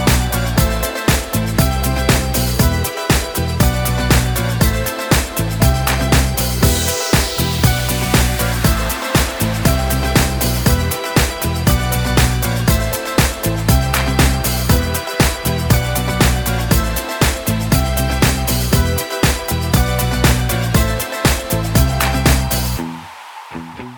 Explicit Version Pop (2010s) 3:37 Buy £1.50